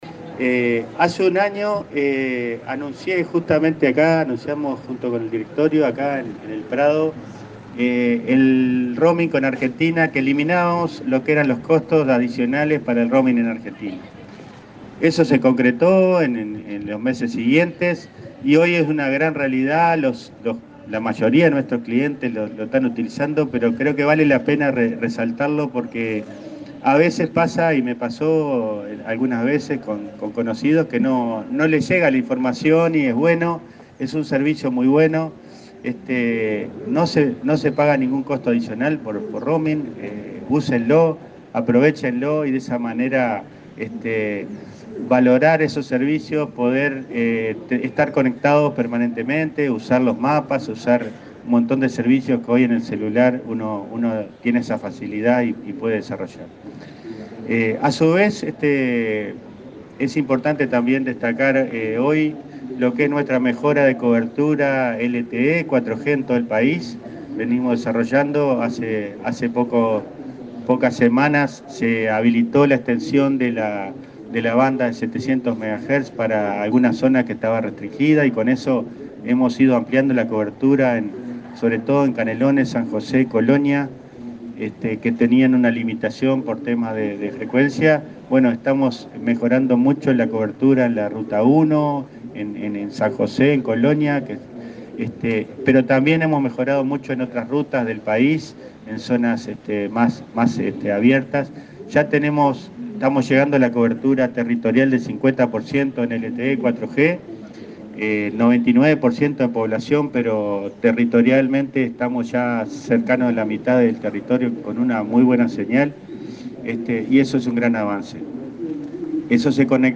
El presidente de Antel, Andrés Tolosa, destacó este viernes en la Rural del Prado, que el ente instaló dos radiobases de tecnología 5G, en La Barra en Maldonado y en Nueva Palmira (Colonia), y que se agregarán otras dos en Montevideo. Se prevé conectar algunos hogares y empresas a modo de prueba, con precios iguales al servicio fijo de internet. Destacó la mejora de cobertura en LTE y 4G y el alcance de la fibra óptica.